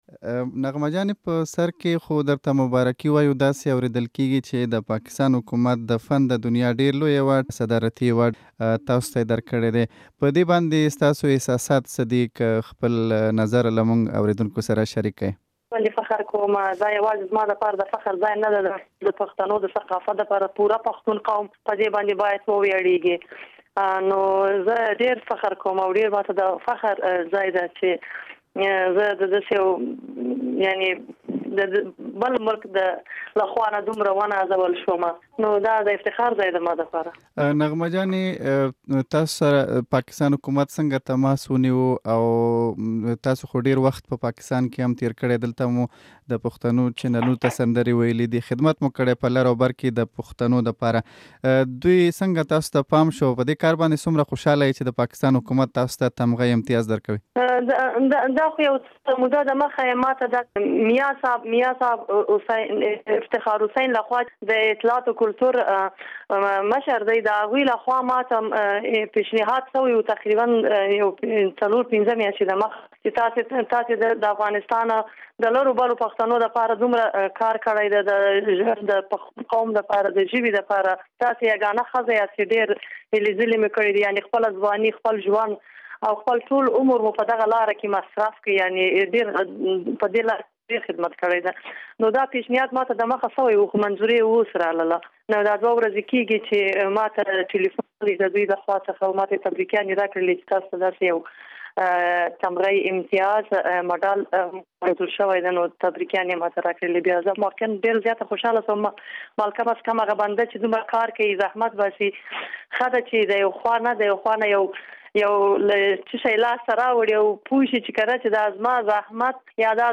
نغمه دمشال سره خبرې کوي